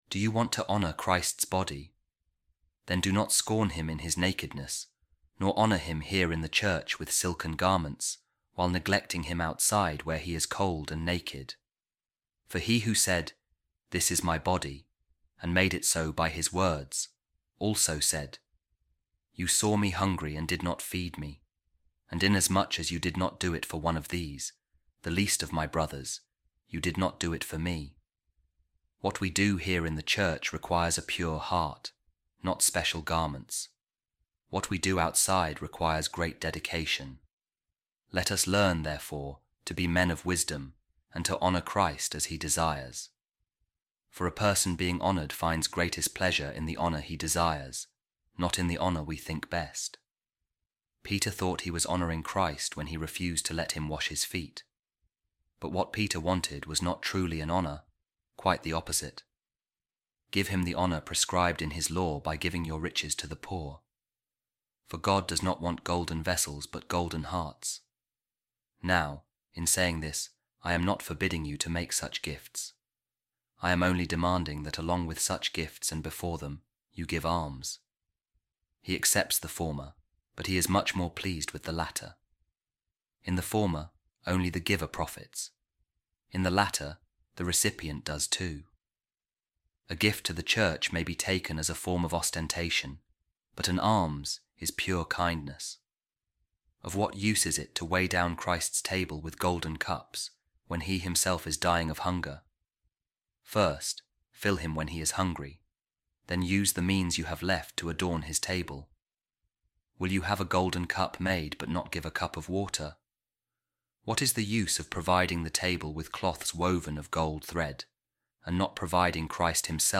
Audio Daily Bible